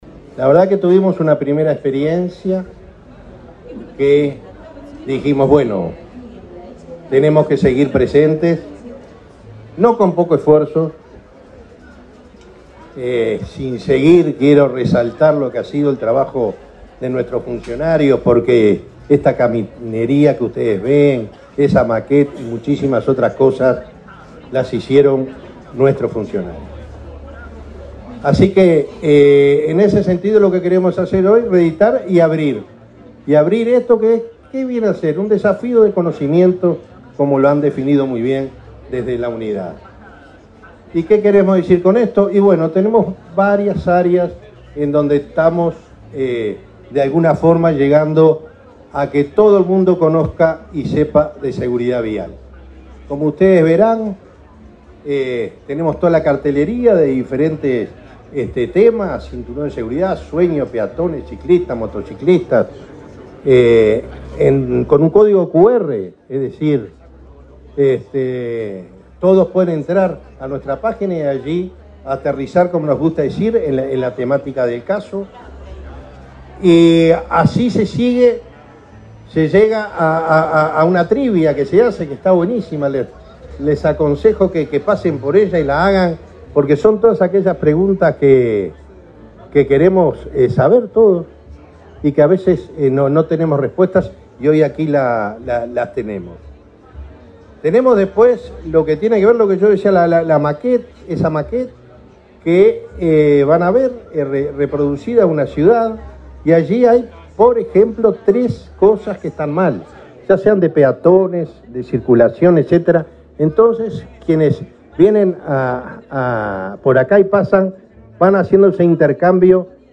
Palabras del presidente de Unasev, Alejandro Draper
Palabras del presidente de Unasev, Alejandro Draper 11/09/2023 Compartir Facebook X Copiar enlace WhatsApp LinkedIn Este lunes 11, el presidente de la Unidad Nacional de Seguridad Vial (Unasev), Alejandro Draper, participó en la inauguración del stand de esa repartición del Estado en la Expo Prado.